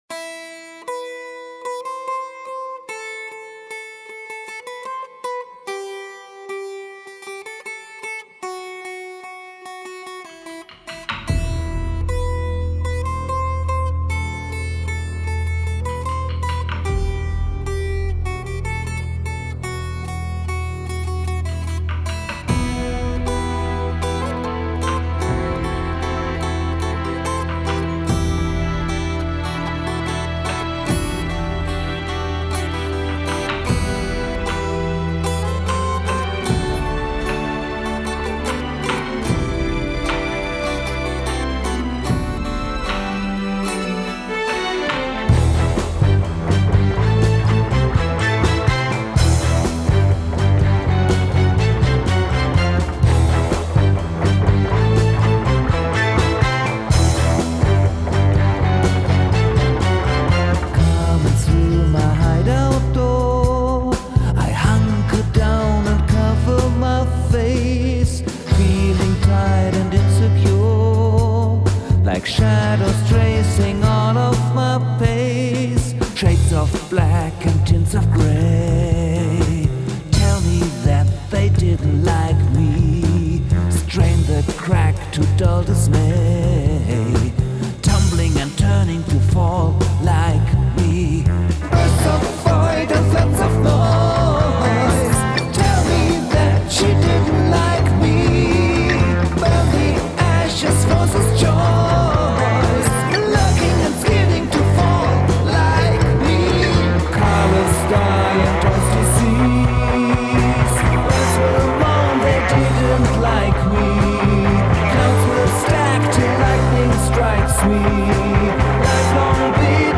Bass
8 beats into 3 + 5
to make it sound like prog.